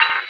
Lo Fi Twoink.wav